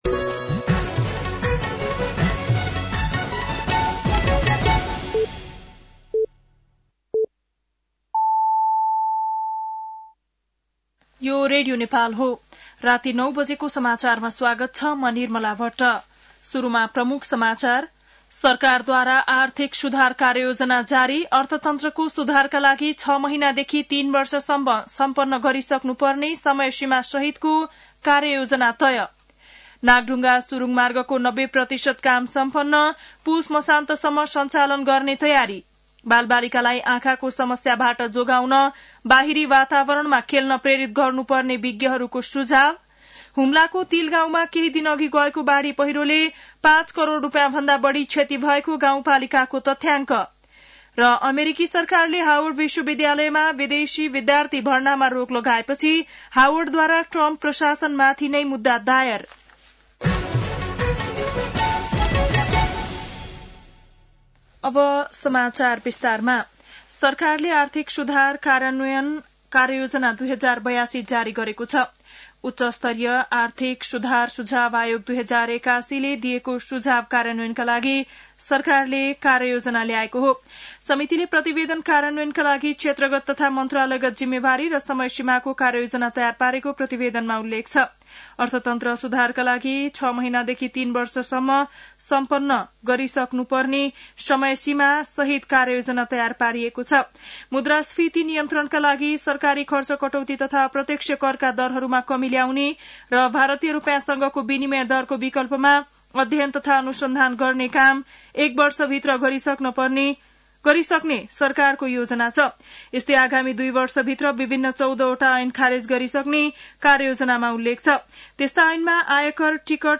बेलुकी ९ बजेको नेपाली समाचार : ९ जेठ , २०८२
9.-pm-nepali-news.mp3